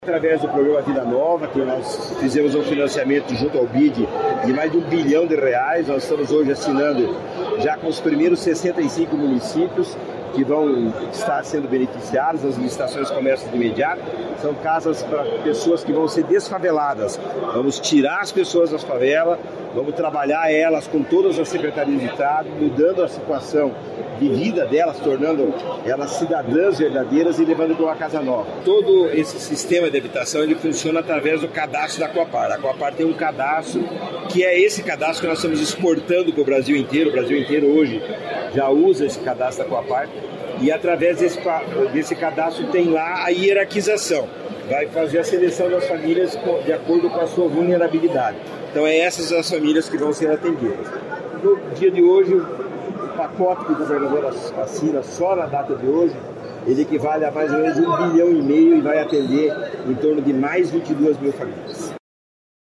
Sonora do diretor-presidente da Cohapar, Jorge Lange, sobre o programa Vida Nova